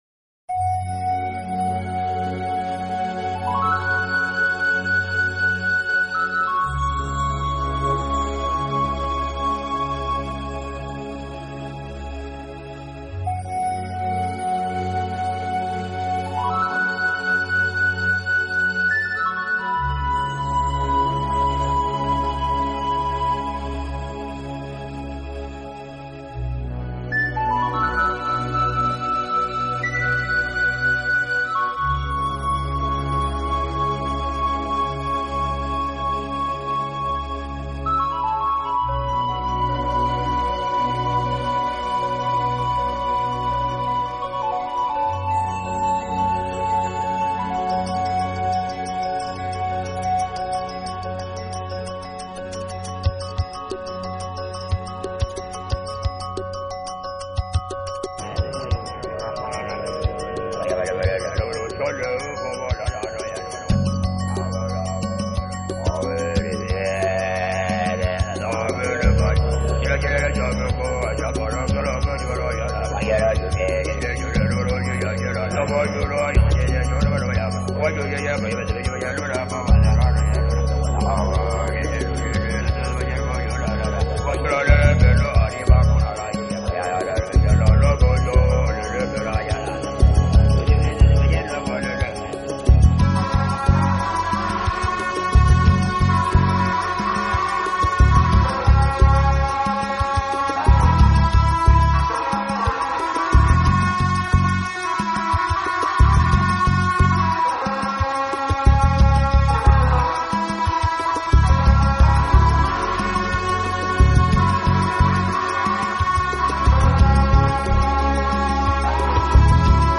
NewAge音乐专辑